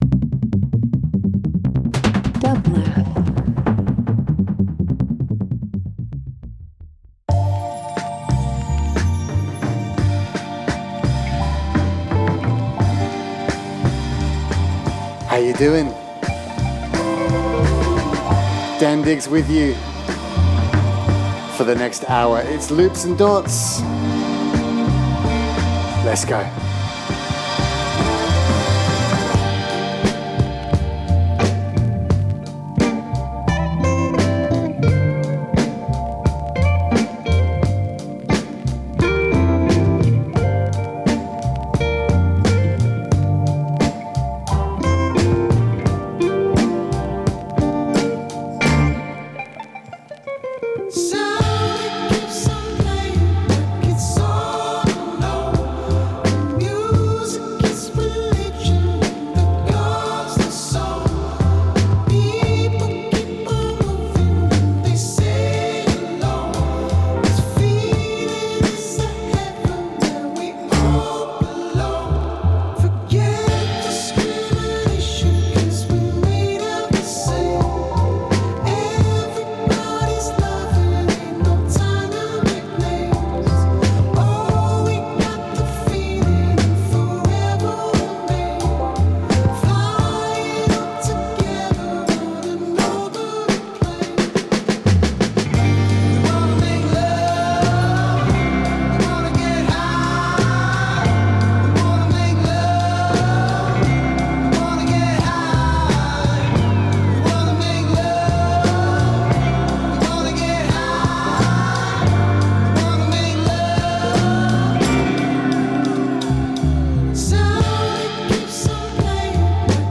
leftfield beats, electronic soul, and experimental sounds